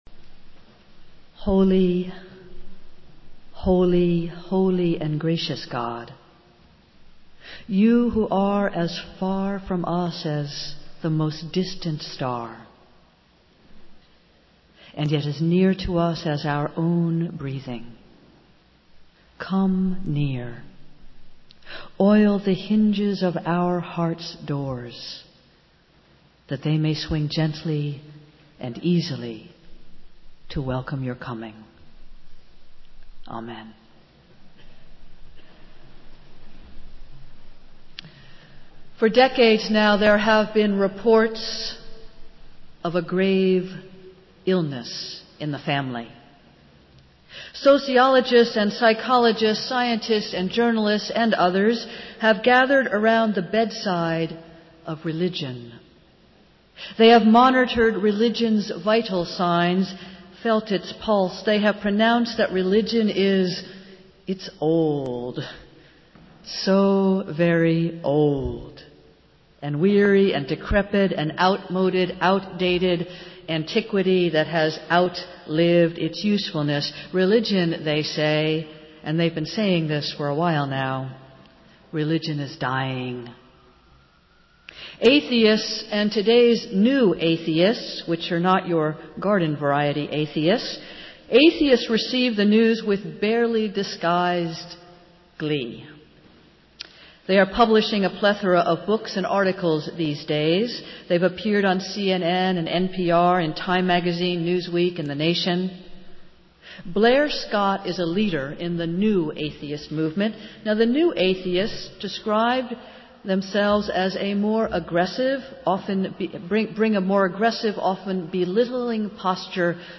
Festival Worship - Fifth Sunday of Easter